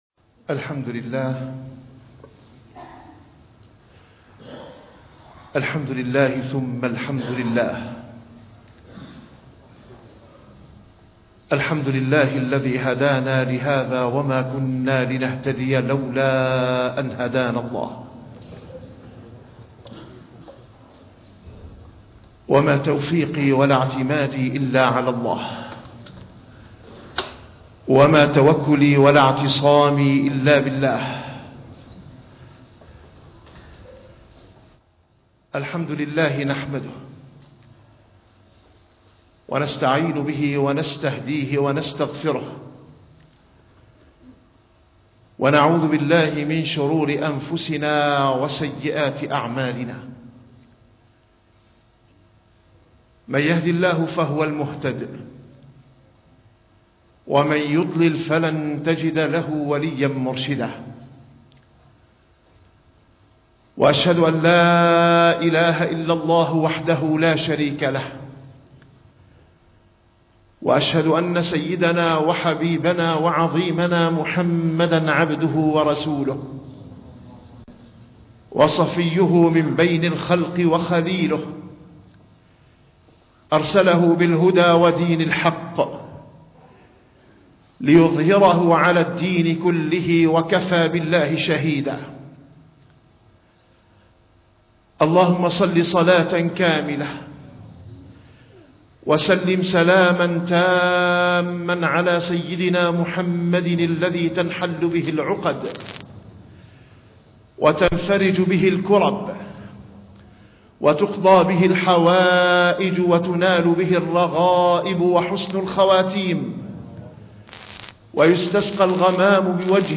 - الخطب - خطبة